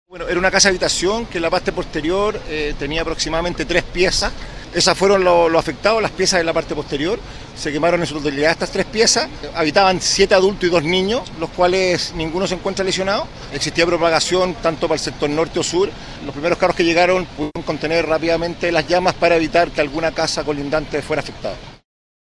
Una vecina del sector dio cuenta de la rápida propagación de las llamas.